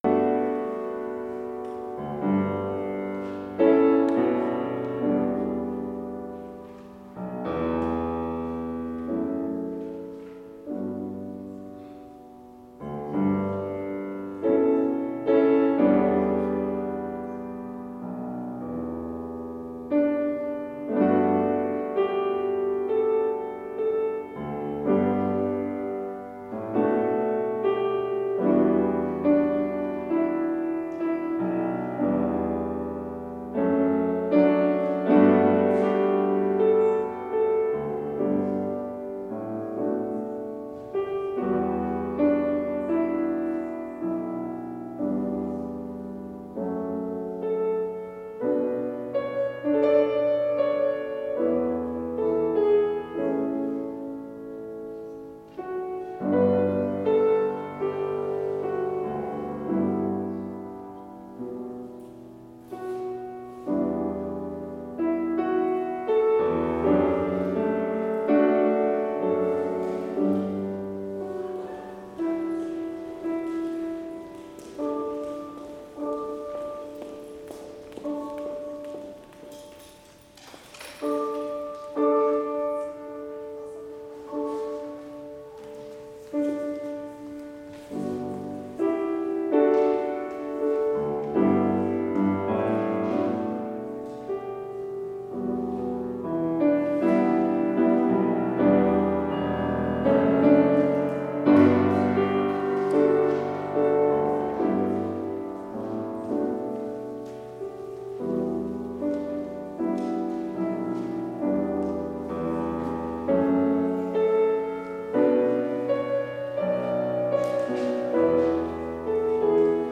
Complete service audio for Chapel - December 4, 2020